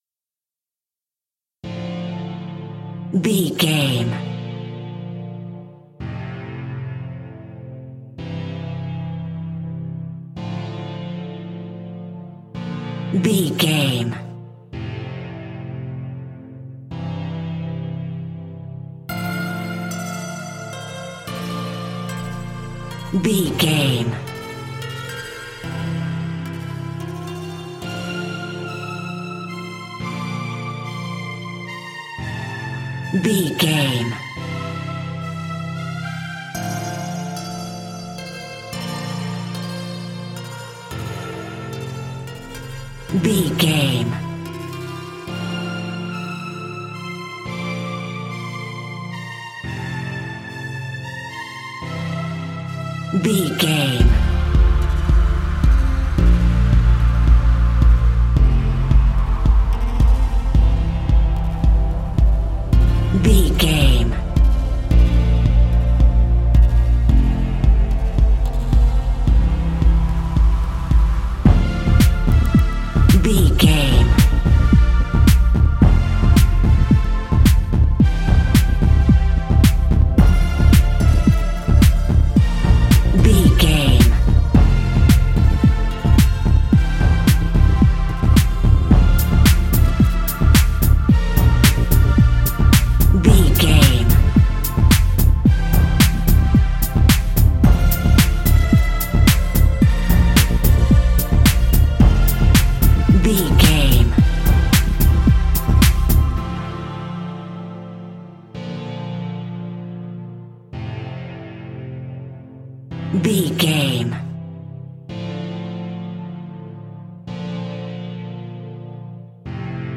Aeolian/Minor
D
groovy
uplifting
futuristic
driving
energetic
repetitive
synthesiser
drum machine
strings
electronic
synth leads
synth bass